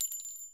Shells